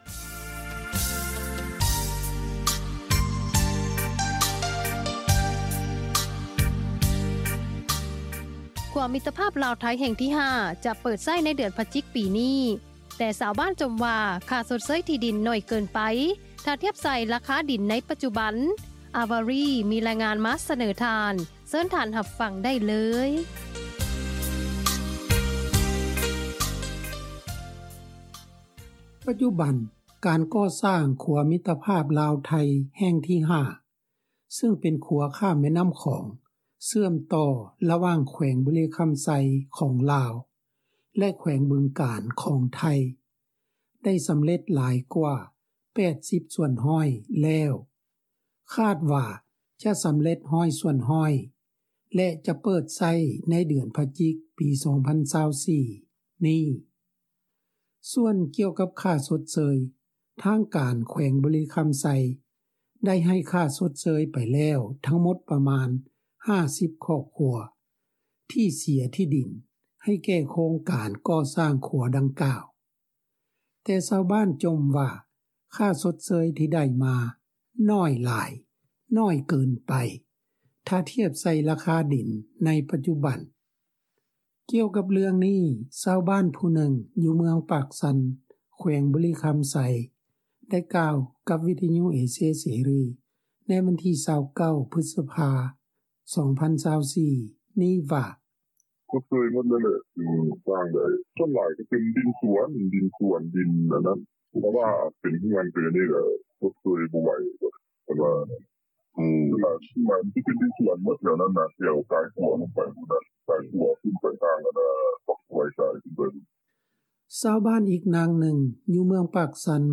ກ່ຽວກັບເລື່ອງນີ້, ຊາວບ້ານ ຜູ້ໜຶ່ງ ຢູ່ເມືອງປາກຊັນ ແຂວງບໍລິຄຳໄຊ ໄດ້ກກັບຕໍ່ວິທຍຸເອເຊັຽເສຣີ ໃນມື້ວັນທີ 29 ພຶດສະພາ 2024 ນີ້ວ່າ.